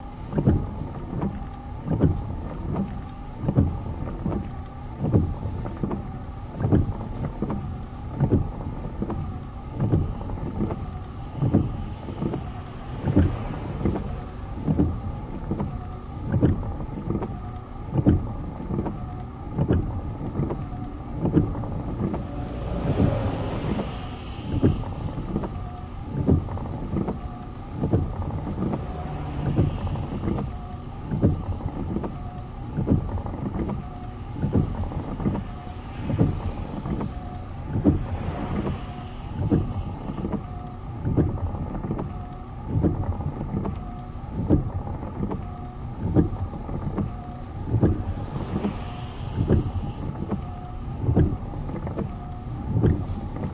挡风玻璃雨刮器 " 挡风玻璃雨刮器无雨
描述：我在去上班的路上，当时正在下雨，我决定记录我在不同条件下的雨刮器声音。这张是雨已经停了，但我的雨刷仍在开着。用H2 Zoom